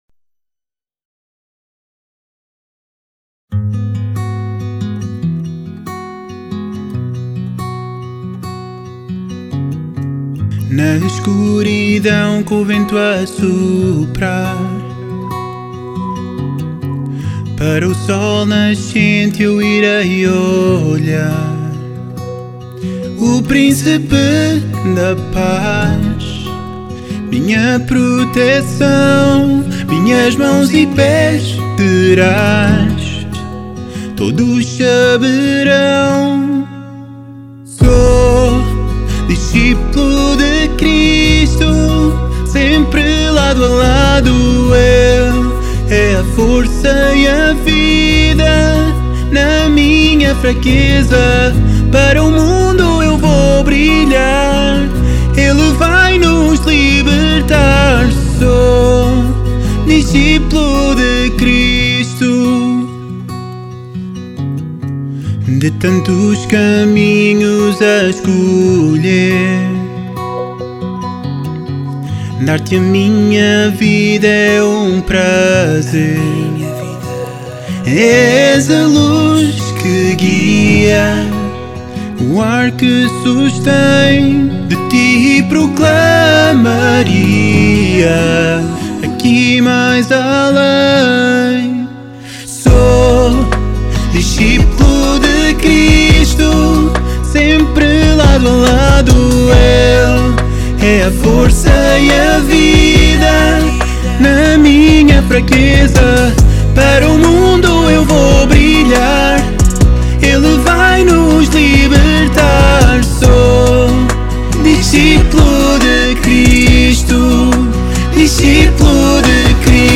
Sacred Music